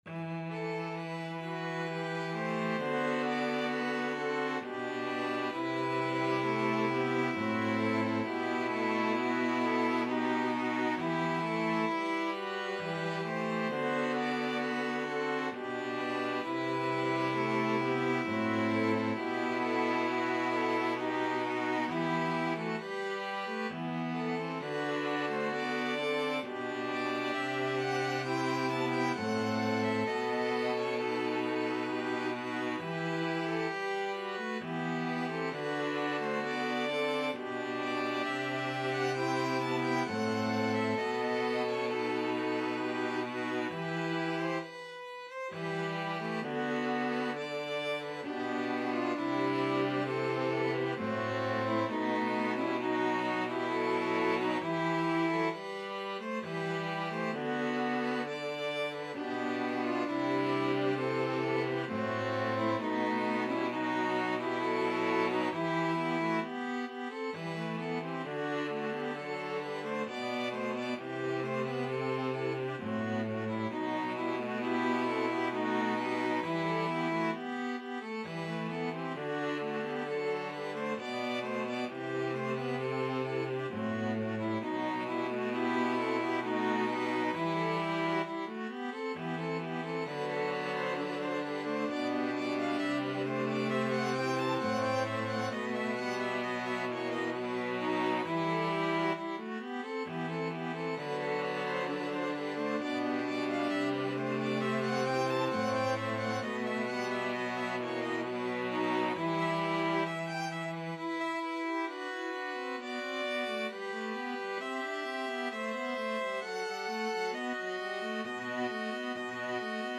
Free Sheet music for String Quartet
Violin 1Violin 2ViolaCello
3/4 (View more 3/4 Music)
E minor (Sounding Pitch) (View more E minor Music for String Quartet )
String Quartet  (View more Advanced String Quartet Music)
Classical (View more Classical String Quartet Music)